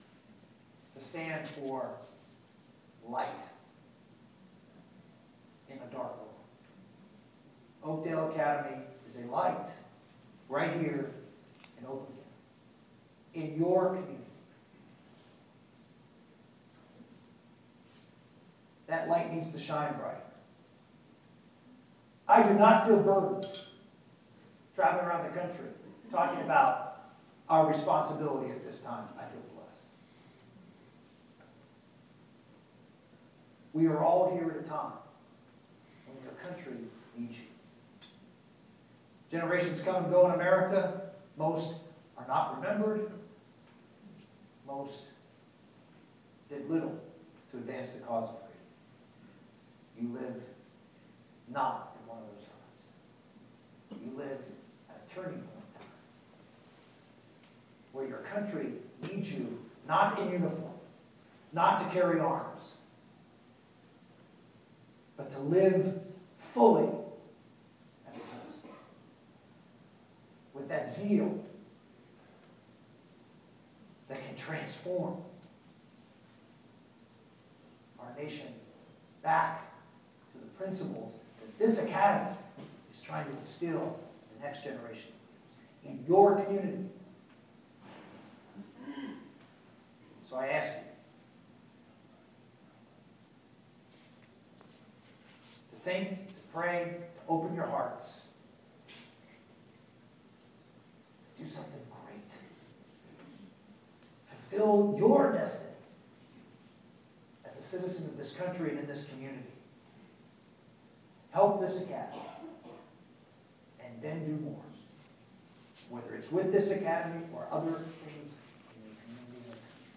MI speech 2.22 part 3